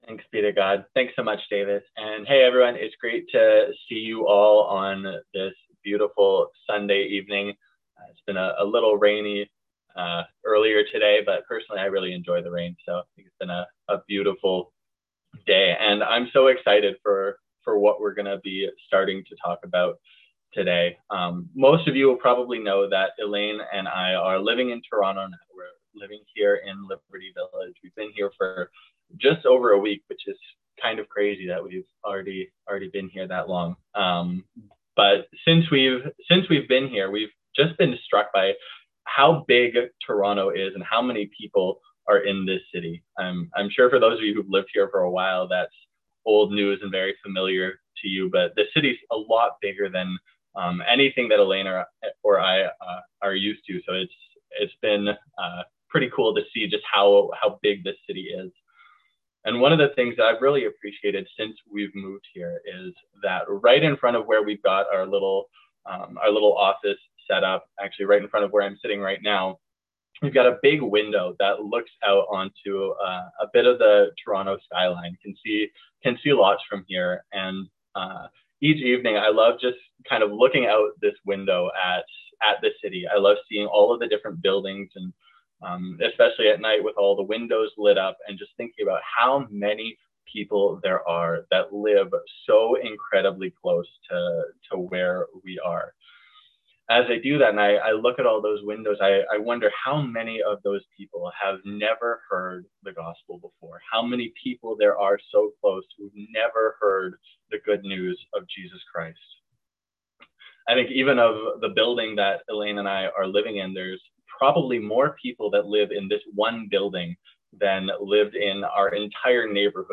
A sermon from Matthew 28:16-20.